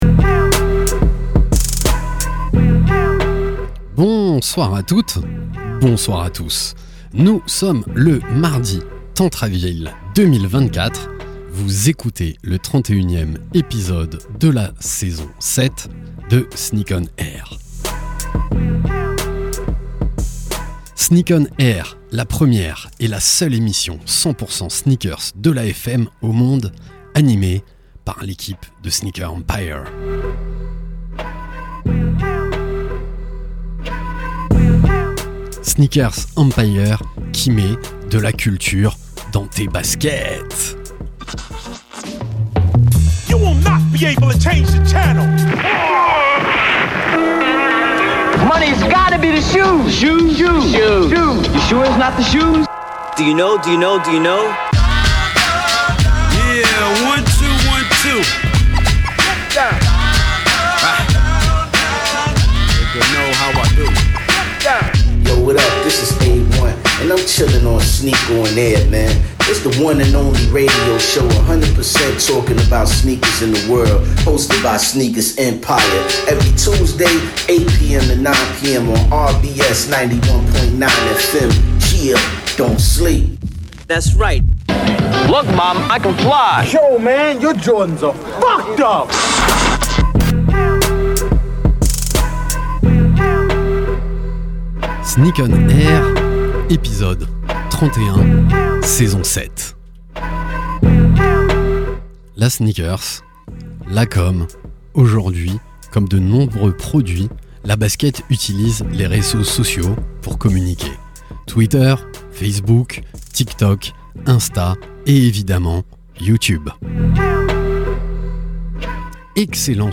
Sneak ON AIR, la première et la seule émission de radio 100% sneakers au monde !!! sur la radio RBS tous les mardis de 20h à 21h. Animée par l’équipe de Sneakers EMPIRE. Actu sneakers, invités, SANA, talk.